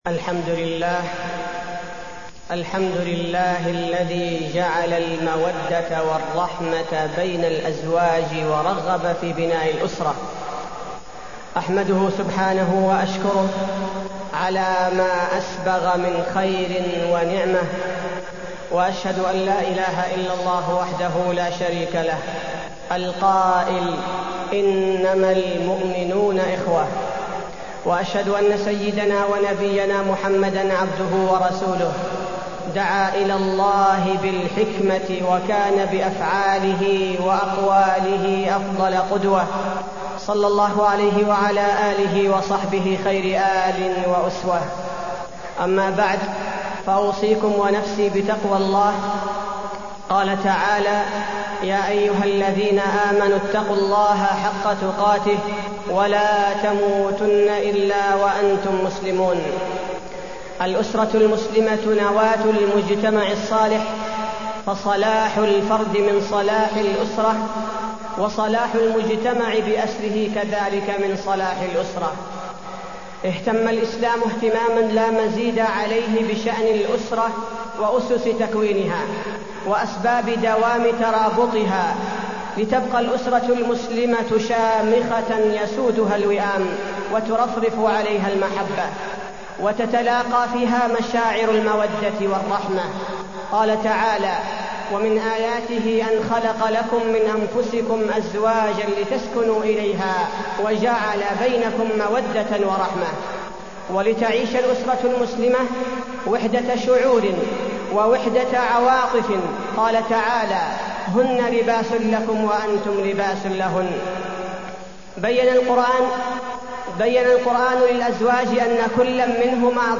تاريخ النشر ١٥ ربيع الثاني ١٤٢٢ هـ المكان: المسجد النبوي الشيخ: فضيلة الشيخ عبدالباري الثبيتي فضيلة الشيخ عبدالباري الثبيتي الأسرة المسلمة